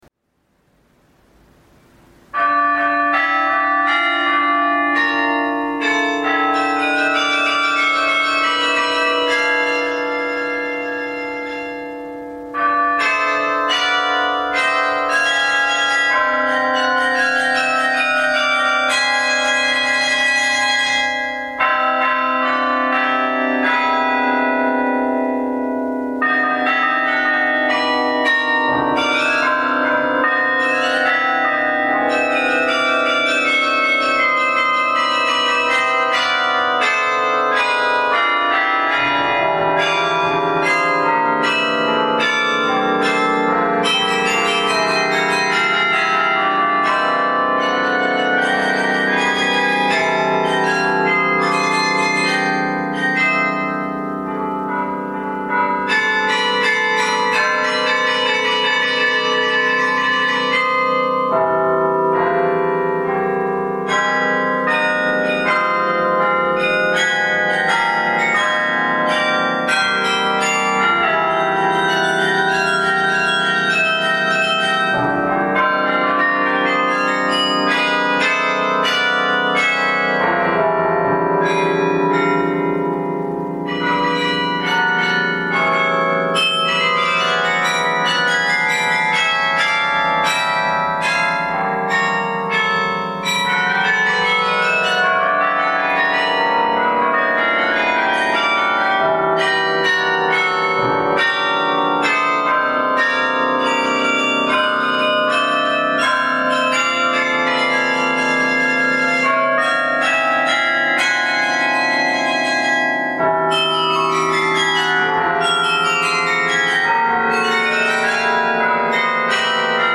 dit is muziek voor klokken.